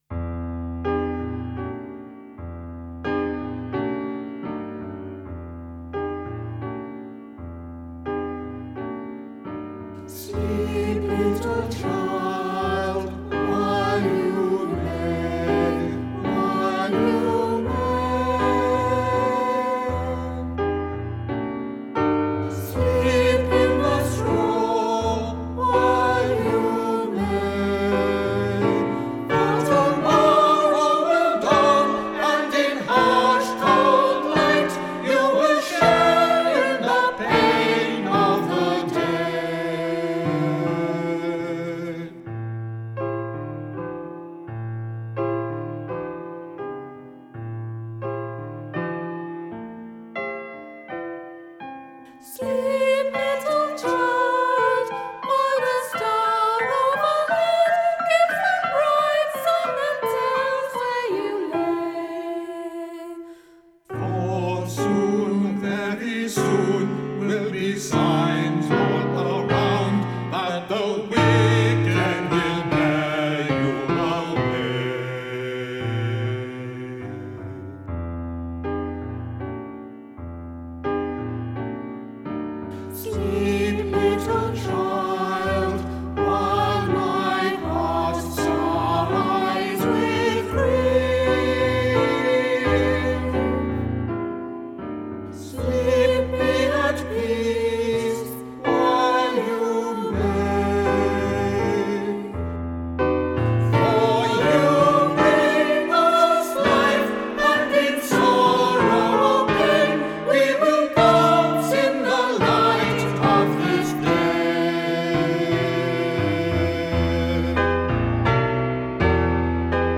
sacred choral compositions and arrangements
Directed from the keyboard